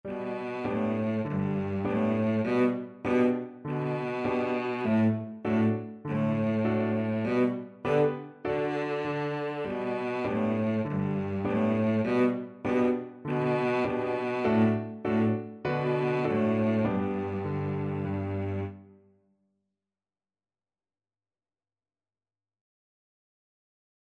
Cello version
4/4 (View more 4/4 Music)
G3-D4
Allegretto